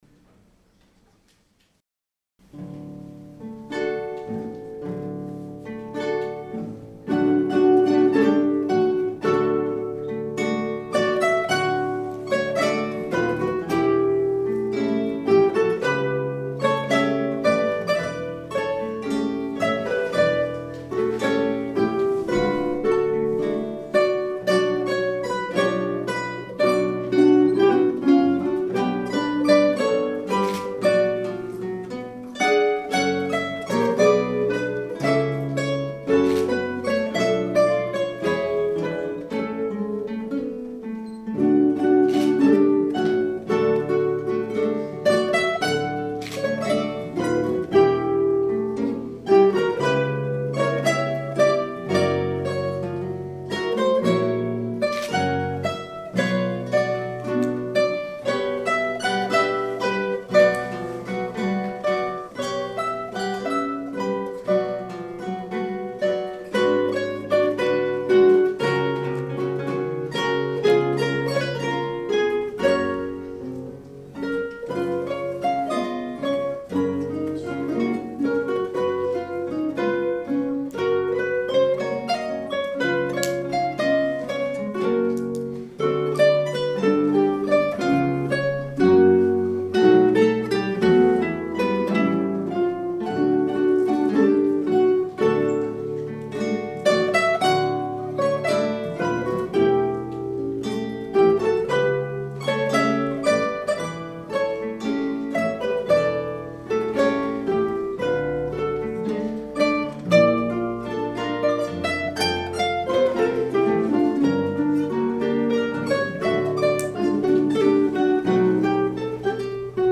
• Kytarový orchestr
Hráči používají klasické kytary ve standardním ladění, 1/8 kytary se sopránovými oktávovými strunami a klasickou basovou kytaru se strunami laděnými o oktávu níže, popř. sólovou elektrickou kytaru (u úprav rockových skladeb). Do některých skladeb bývají také zapojeny perkusní či Orffovy nástroje.